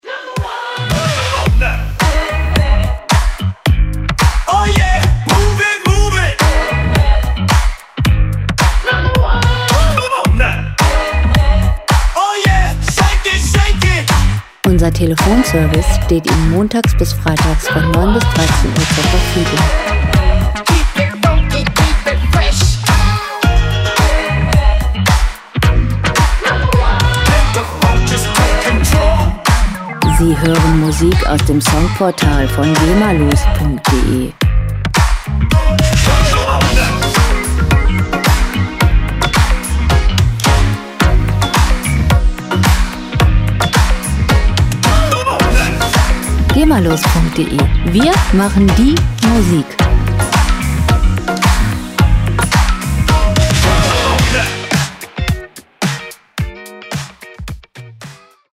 • Modern Funk